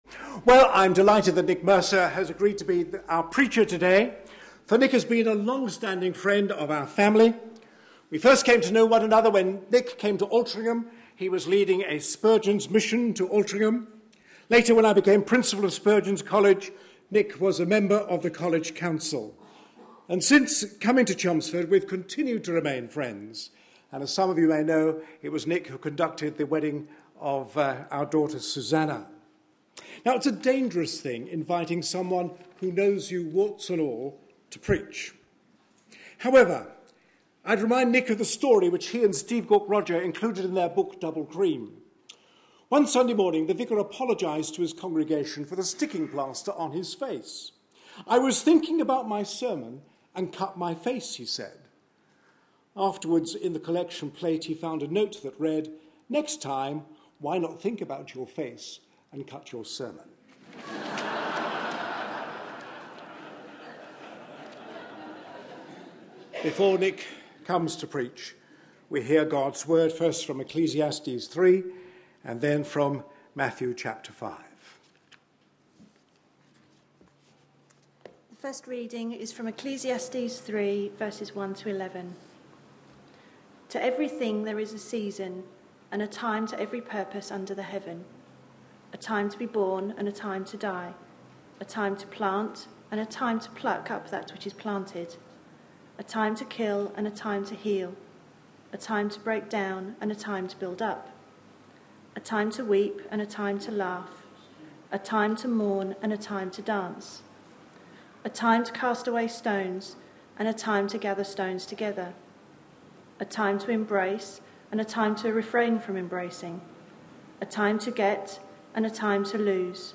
A sermon preached on 9th March, 2014.